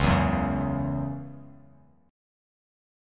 Dbmbb5 chord